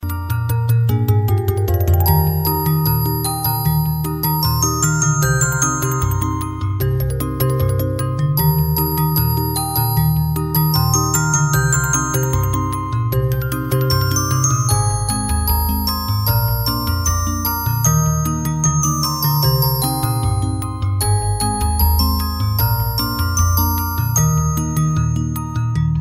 • Качество: 128, Stereo
Музыкальная шкатулка